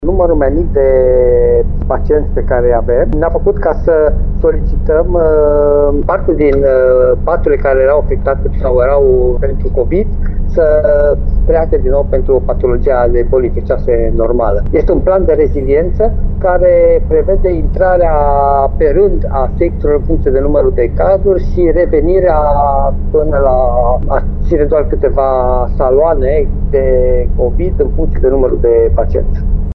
Solicitarea are la bază scăderea semificativă a cazurilor de COVID 19 care mai au nevoie de spitalizare, spune medicul